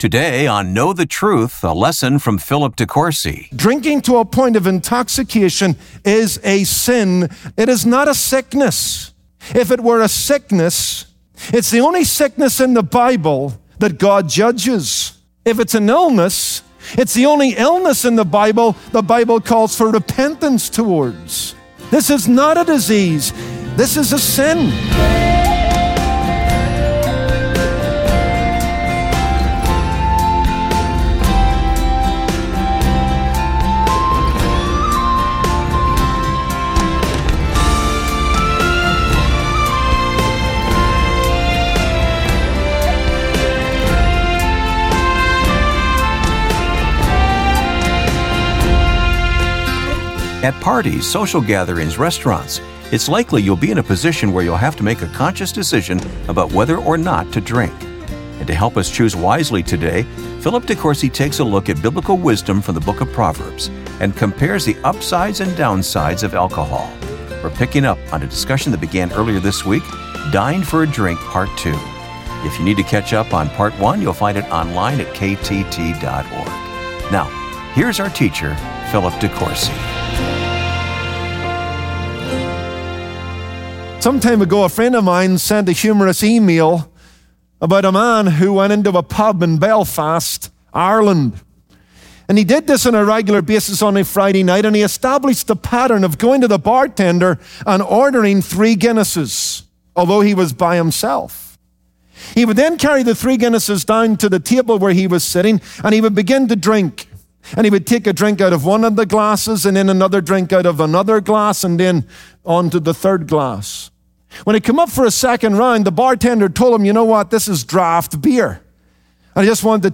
To drink or not to drink? That is a question Christians have debated for centuries. On this Wednesday broadcast.